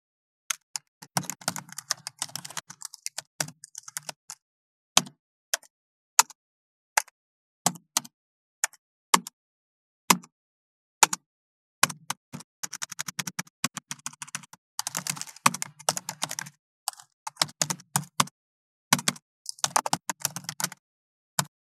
29.タイピング【無料効果音】
ASMRタイピング効果音
ASMR